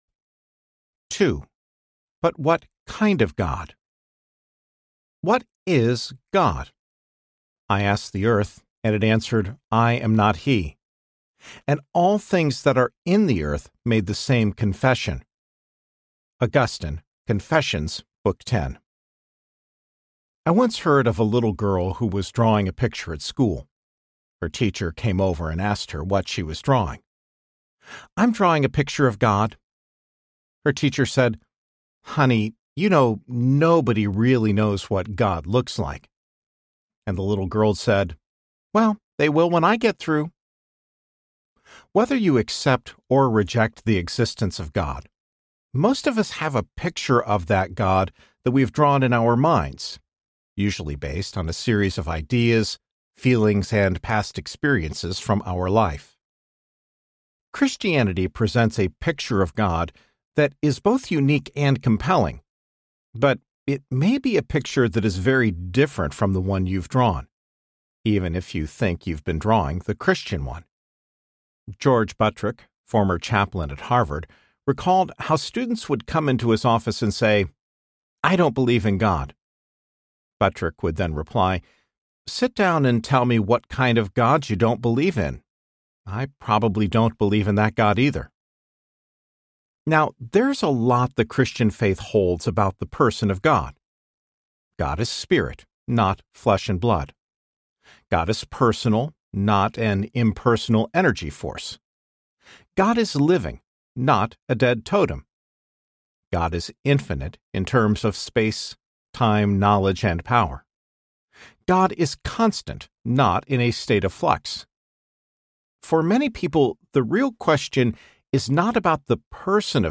Christianity for People Who Aren’t Christians Audiobook
Narrator
7.1 Hrs. – Unabridged